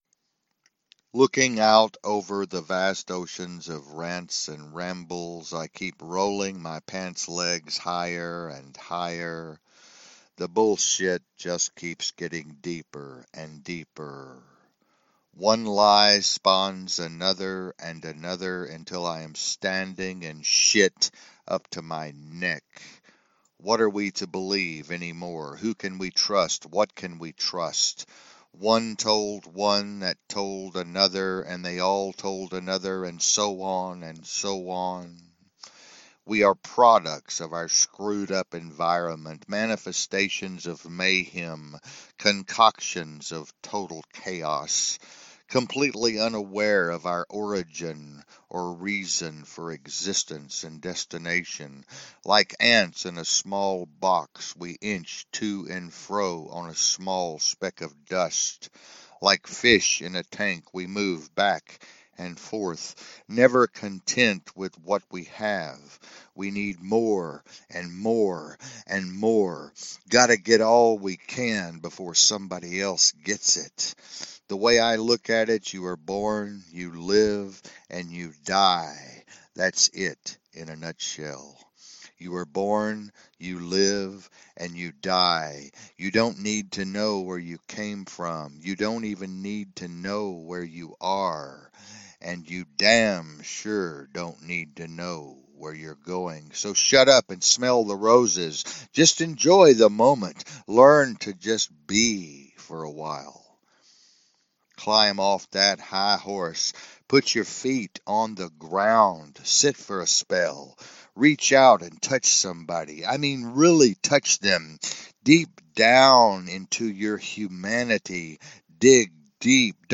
A Spoken Word Piece